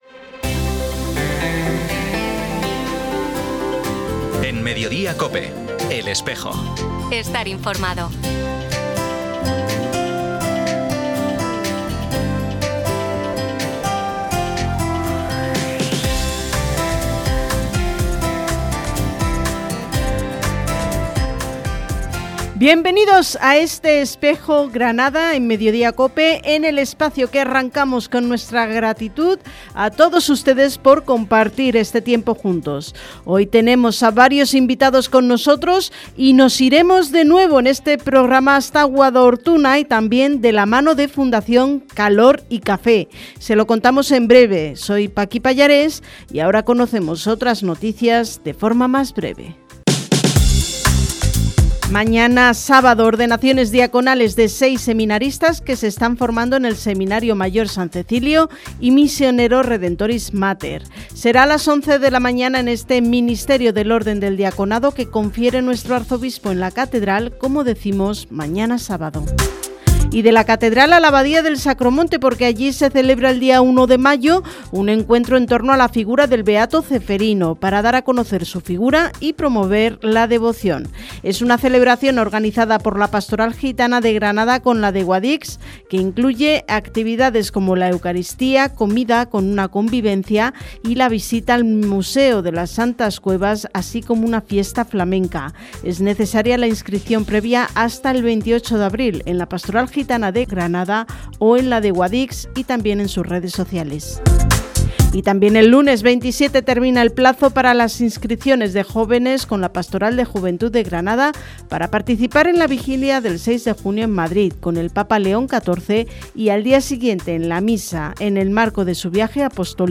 Programa emitido en COPE Granada, el 24 de abril de 2026.